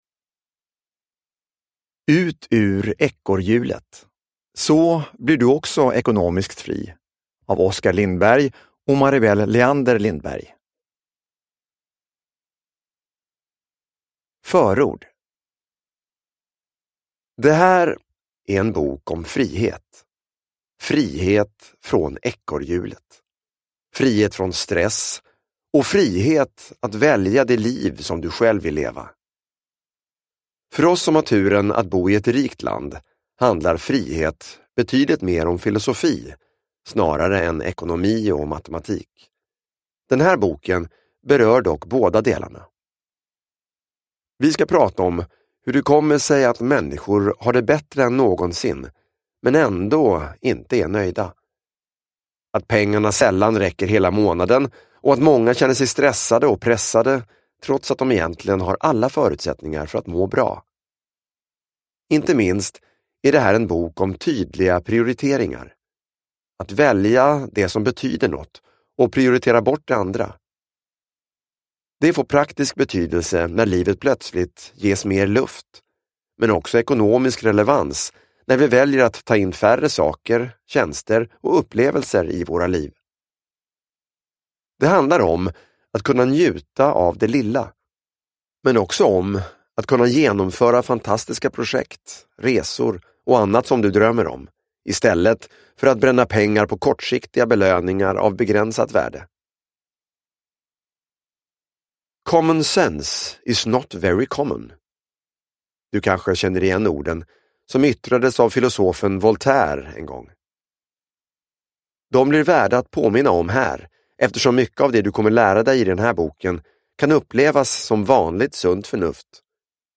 Ut ur ekorrhjulet : så blir du också ekonomiskt fri – Ljudbok – Laddas ner